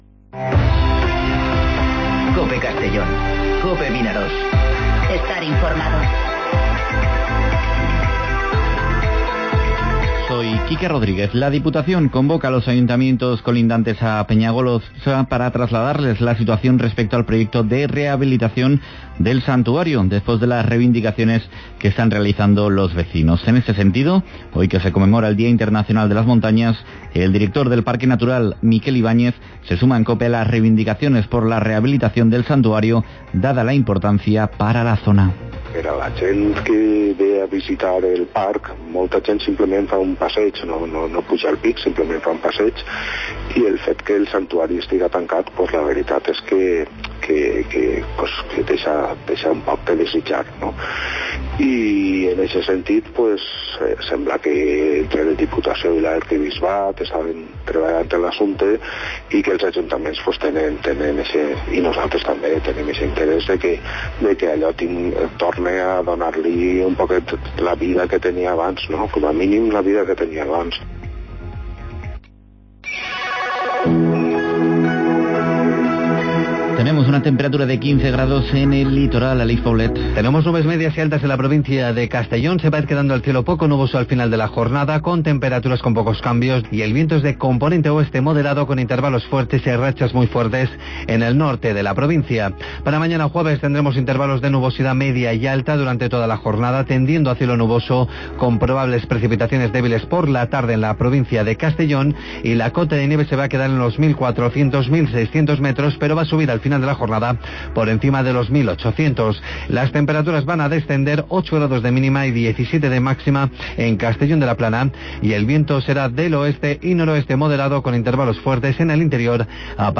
Noticias